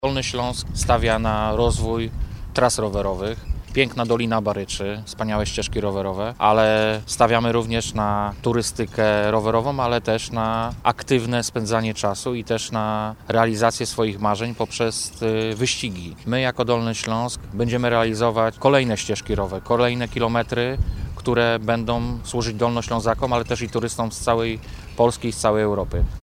– Dolny Śląsk konsekwentnie rozwija ofertę turystyki rowerowej – mówi Wojciech Bochnak, Wicemarszałek Województwa Dolnośląskiego.